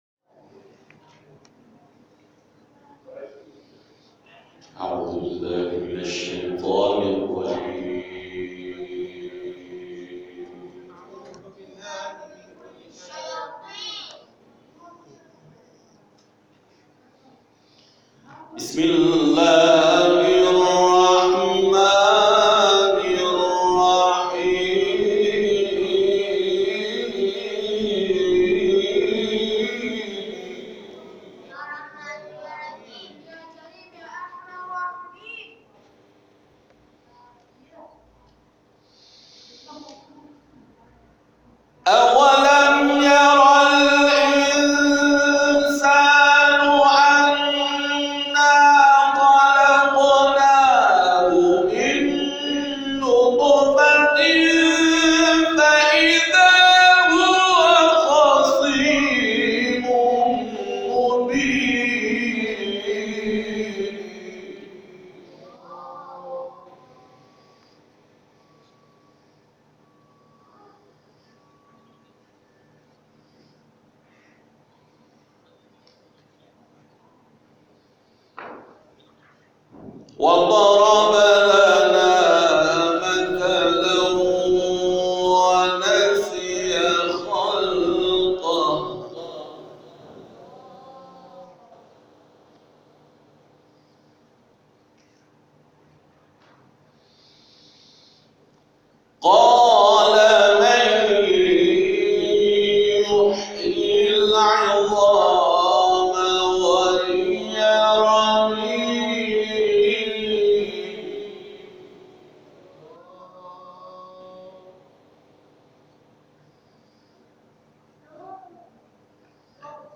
این تلاوت کوتاه روز شنبه 23 دی ماه در مسجد حضرت رقیه(س) واقع در شهرک امام حسین(ع) اسلامشهر در جلسه آموزش قرآن